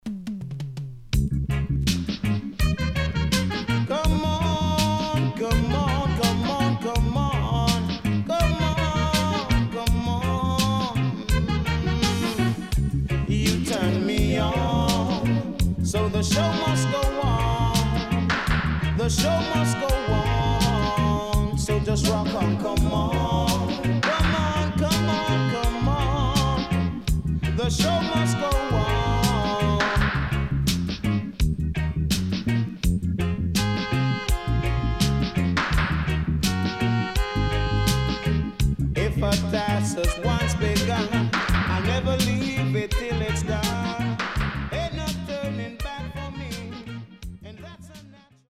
HOME > LP [DANCEHALL]  >  EARLY 80’s